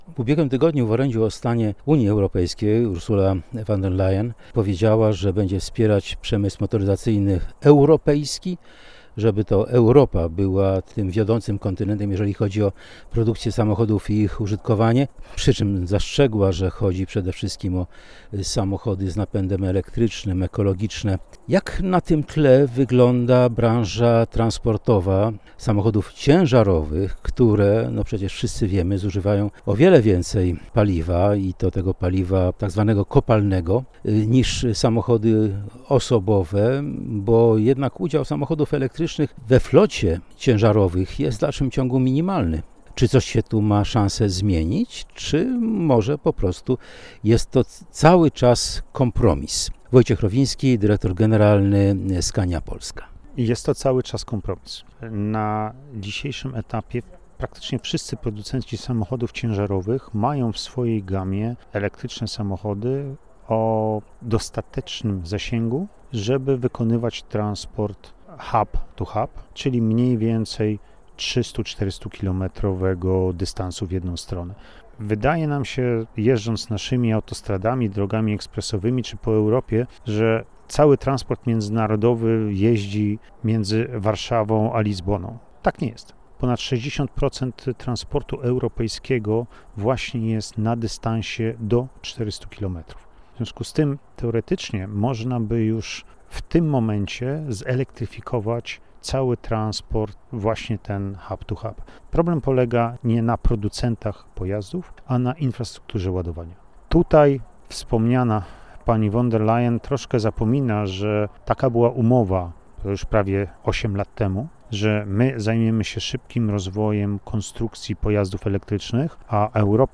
Elektryczne ciężarówki przyszłością transportu drogowego? Rozmowa z ekspertem